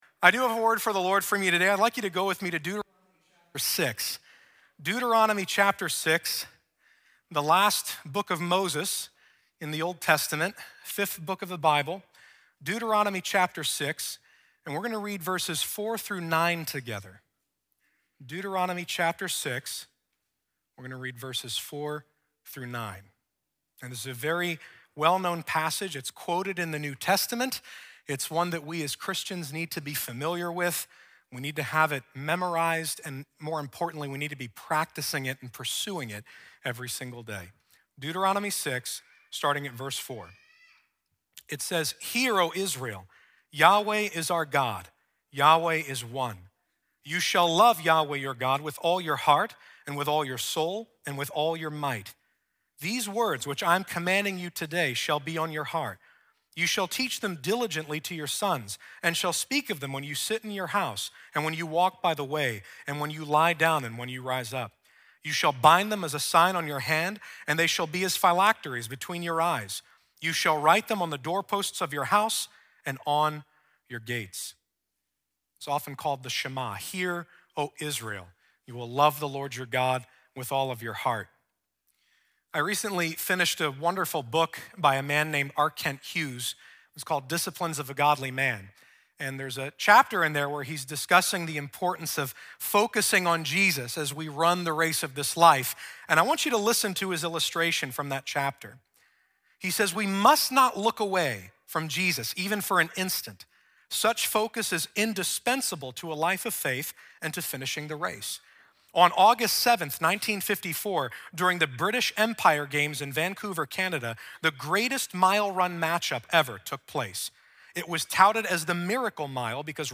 Our messages are recorded at Times Square Church in New York City.